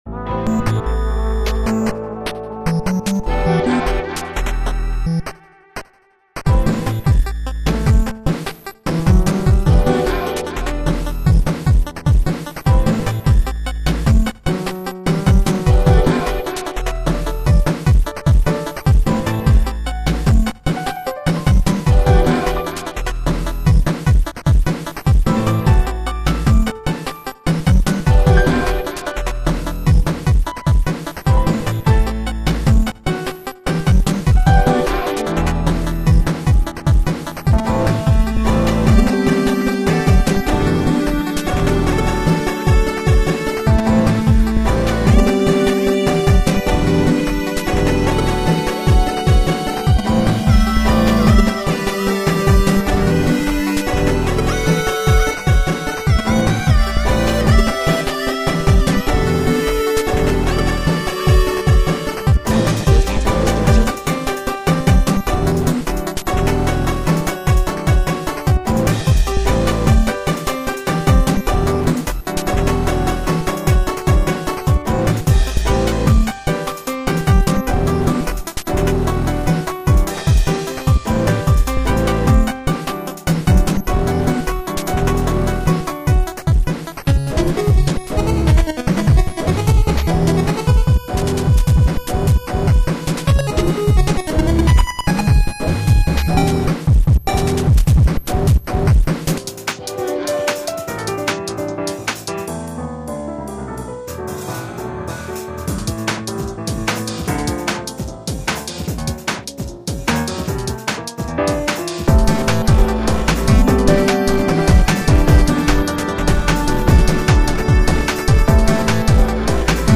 ★☆  "The samples integrate well.
★☆  "Good blend between nes and more elaborate synth sound.